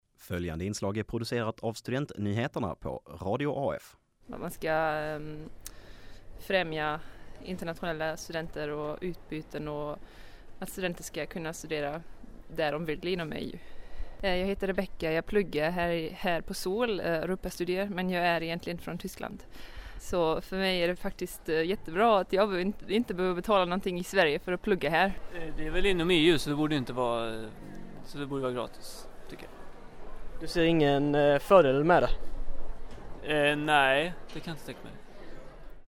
Danmark inför avgifter även för svenska studenter som inte ingår i utbytesavtal. Radio AF har pratat med Lundastudenter som upprörs över förändringarna.